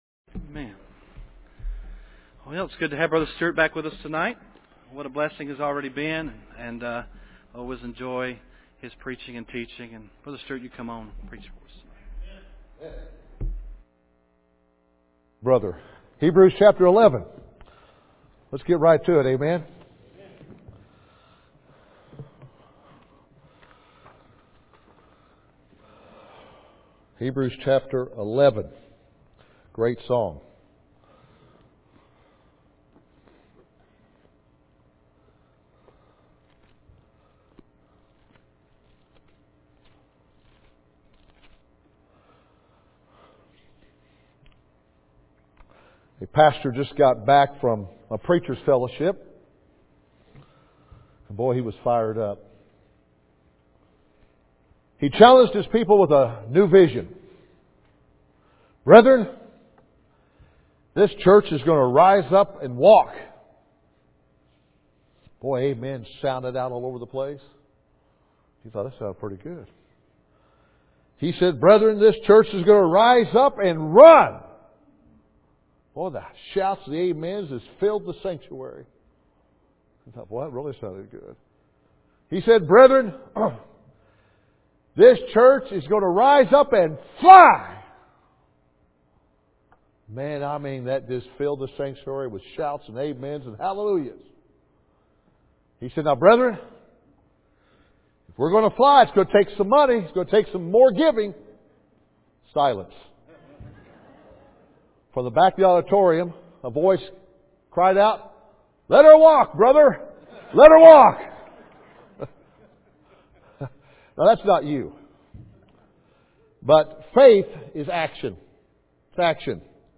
030414 - Bible Conference - Part 4